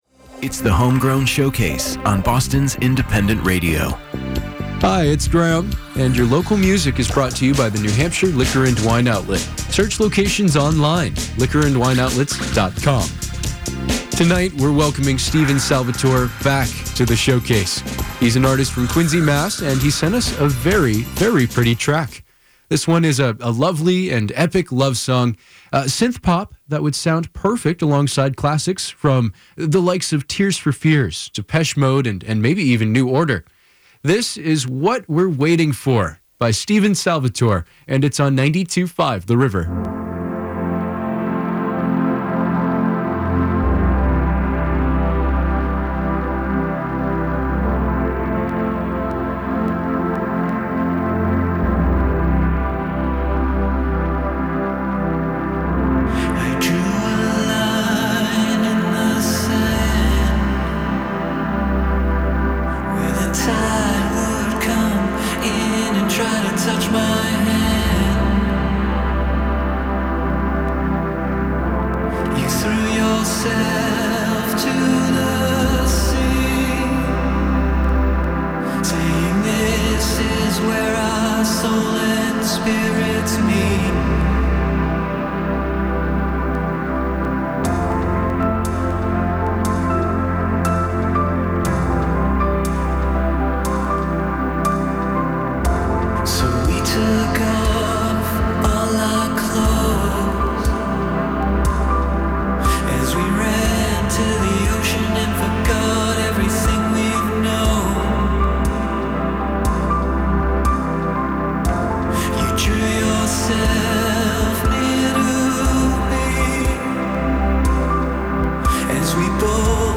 Drums, acoustic guitars
Bass, piano, synth, strings